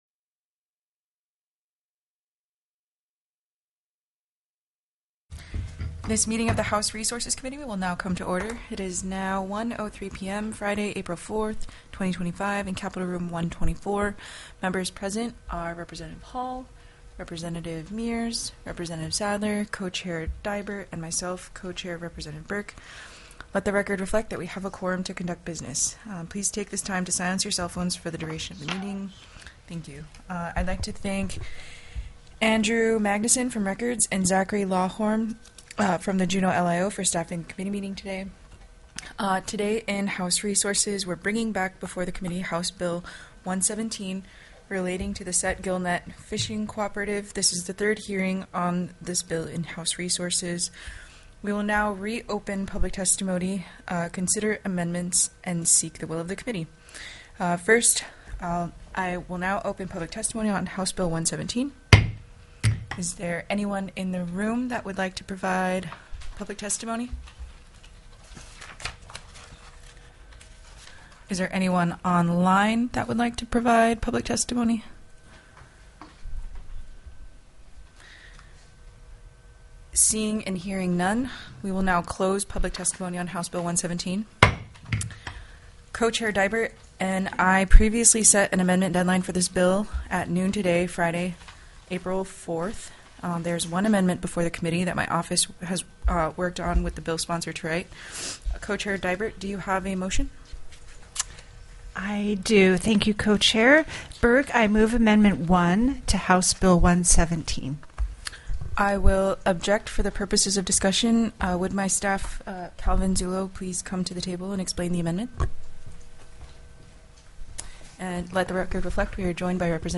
+ teleconferenced
-- Public Testimony --